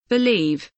believe kelimesinin anlamı, resimli anlatımı ve sesli okunuşu